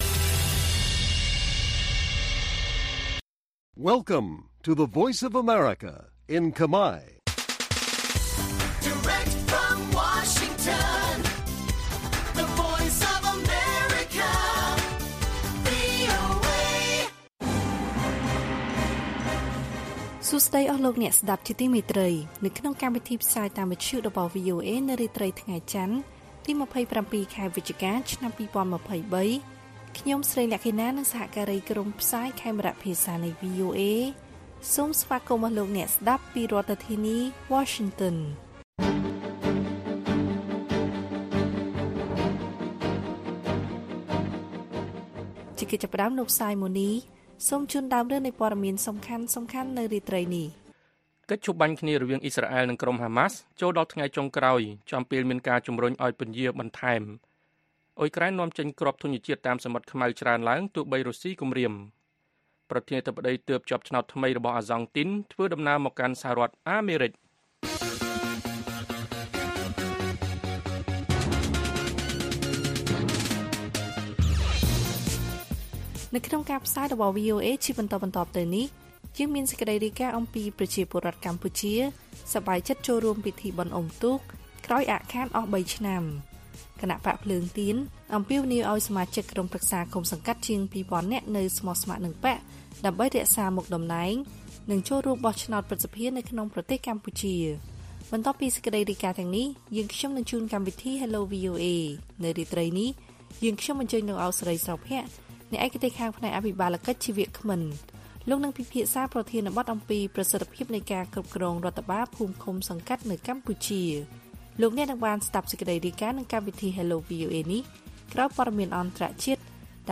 ព័ត៌មានពេលរាត្រី ២៧ វិច្ឆិកា៖ ប្រជាពលរដ្ឋកម្ពុជាសប្បាយចិត្តចូលរួមពិធីបុណ្យអុំទូក ក្រោយអាក់ខានអស់៣ឆ្នាំ